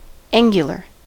angular: Wikimedia Commons US English Pronunciations
En-us-angular.WAV